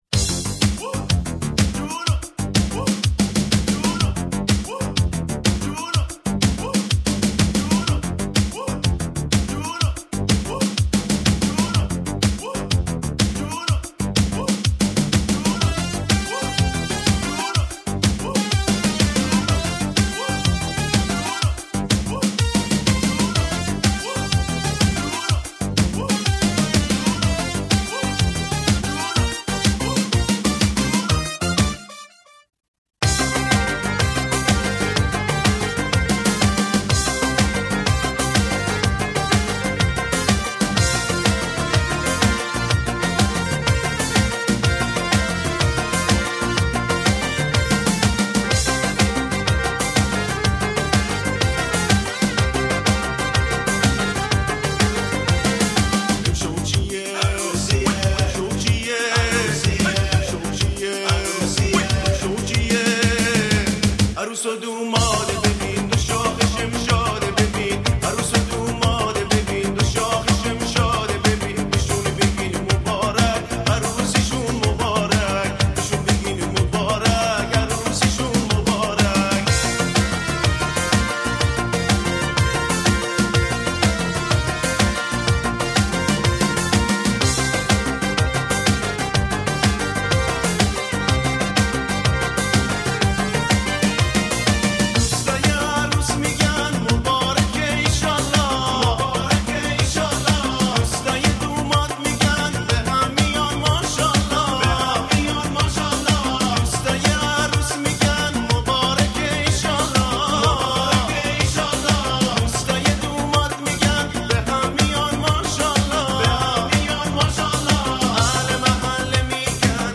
مخصوص عروسی و مراسم